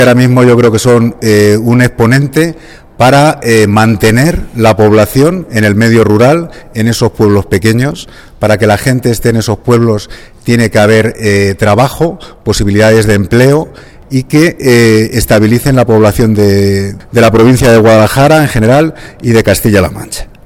El director general de Desarrollo Rural habla de la importancia de los Grupos de Acción Local.